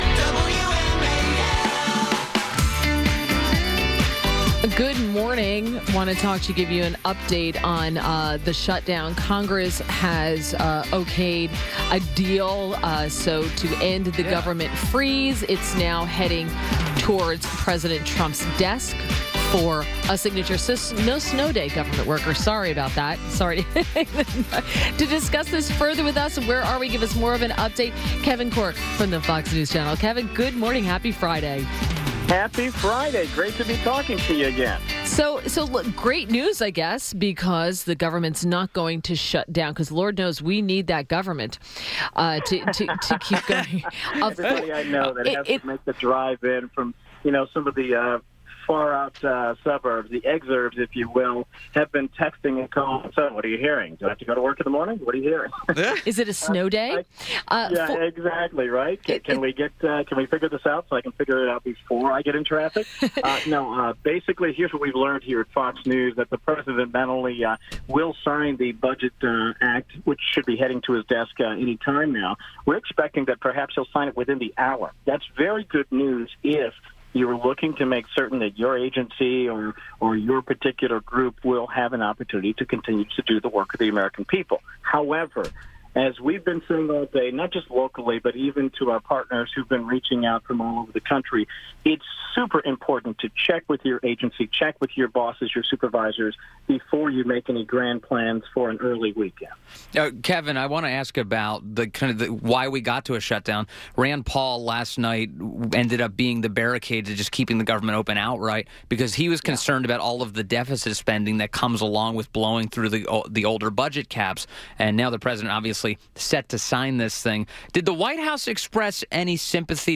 INTERVIEW -- KEVIN CORKE - White House Correspondent for Fox News Channel – discussed the latest White House news.